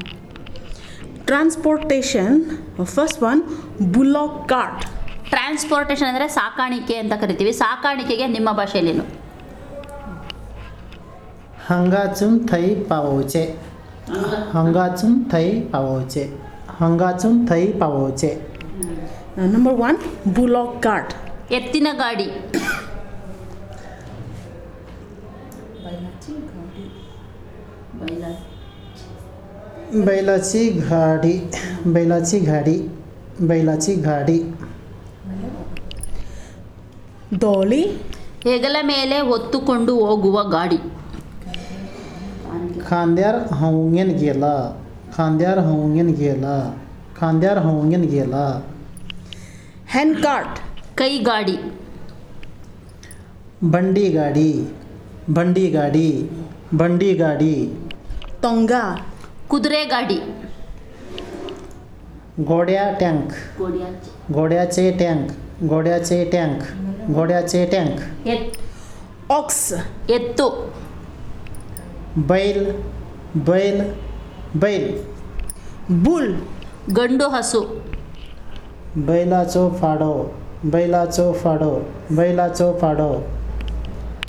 Elicitation of words about transportation